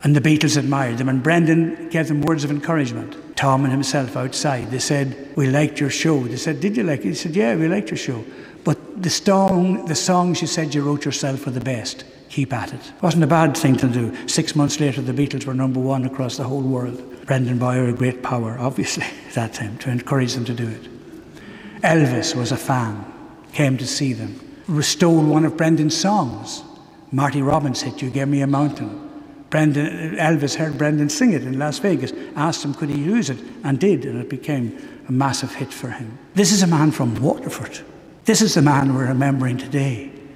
Brendan died in Las Vegas in 2020 but hundreds of people packed into the Cathedral of the Most Holy Trinity Within for his memorial service today.
Fr Brian D’arcy spoke fondly of his friend and the legacy he left behind at the funeral: